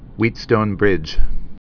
(wētstōn, hwēt-) also Wheat·stone's bridge (-stōnz)